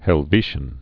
(hĕl-vēshən)